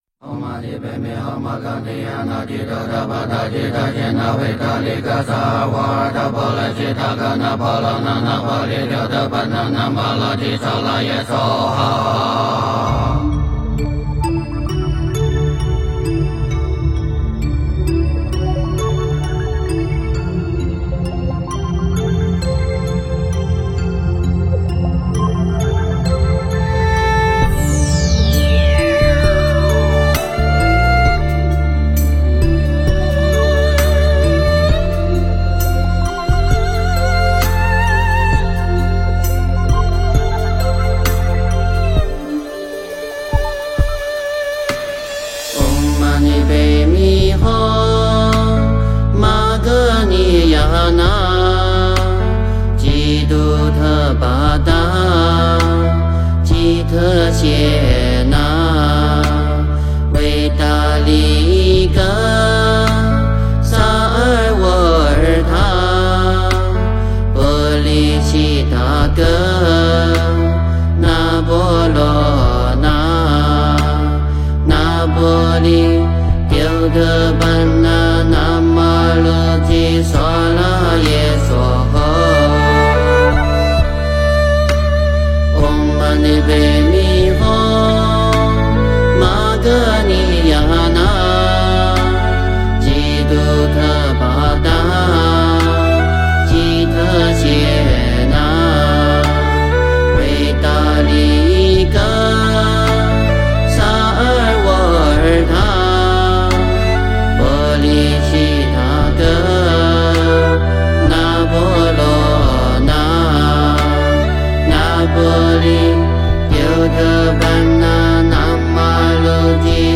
佛音 诵经 佛教音乐 返回列表 上一篇： 观世音菩萨普门品 下一篇： 回向文 相关文章 心经 心经--推...